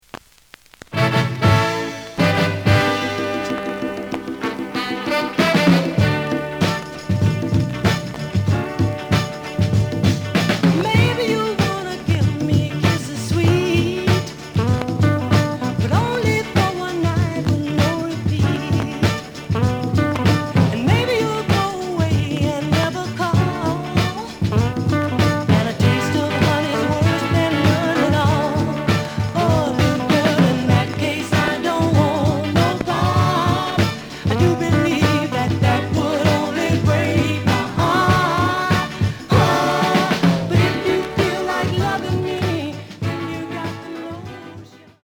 The listen sample is recorded from the actual item.
●Genre: Soul, 60's Soul
Some click noise on A side due to scratches.